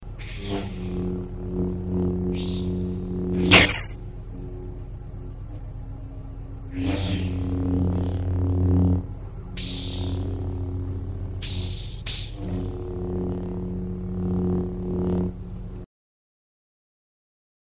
Catégorie Effets Sonores